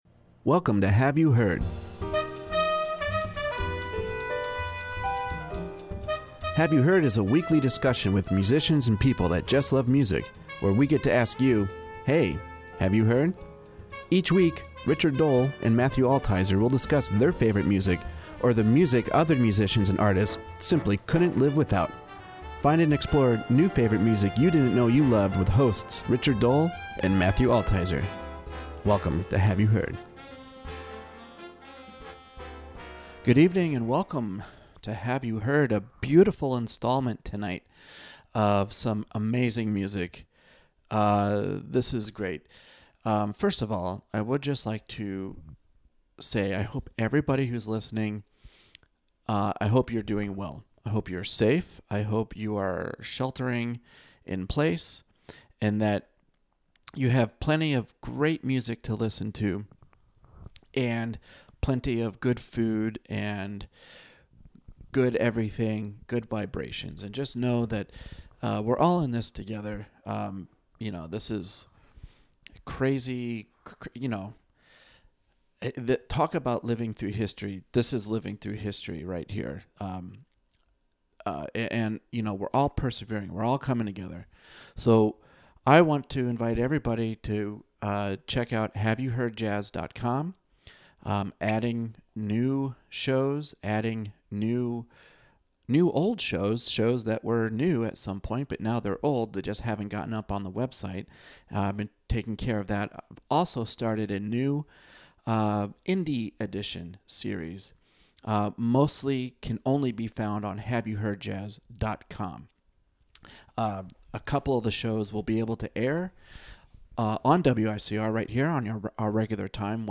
We had a great conversation with Aaron.